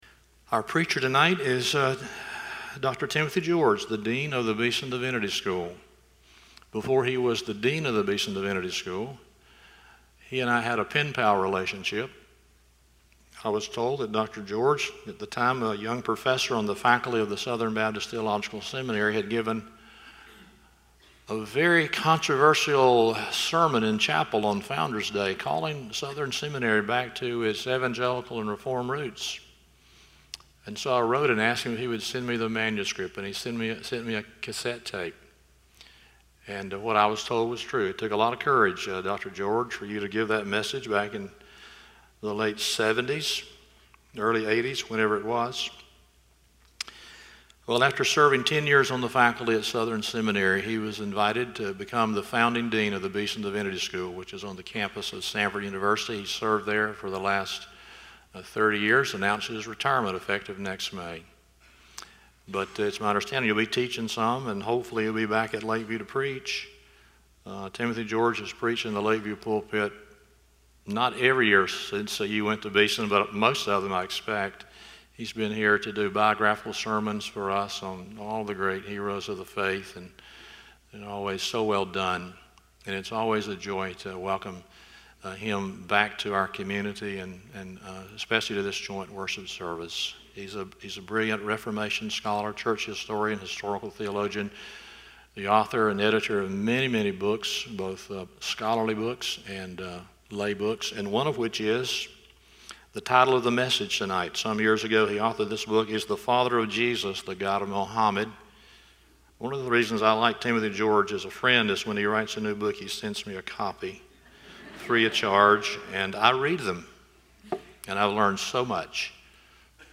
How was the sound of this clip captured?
John 1:1-5 & 14-18 Service Type: Sunday Evening How are we to understand Islam in the light of a Christian faith?